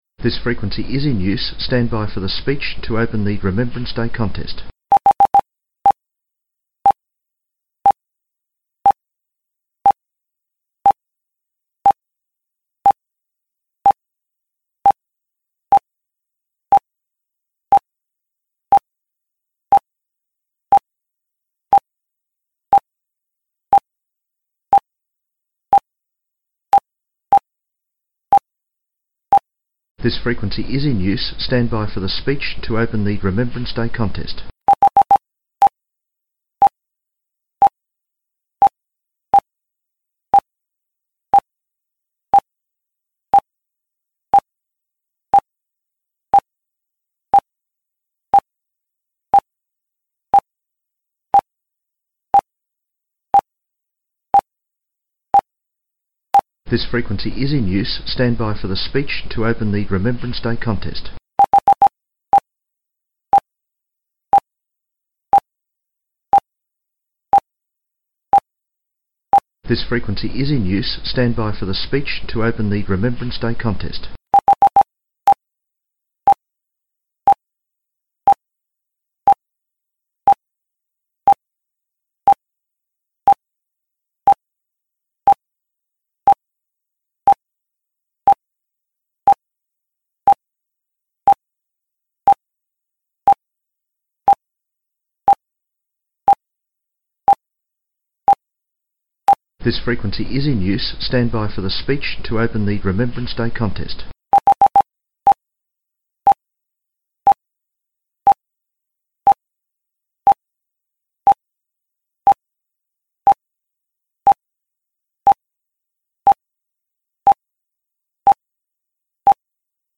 Remembrance Day Contest Opening Address
It is preceded by a short opening address by a Guest Speaker transmitted on various WIA frequencies during the few minutes prior to the contest. During this ceremony, a roll call of amateurs who paid the supreme sacrifice during WWII is read.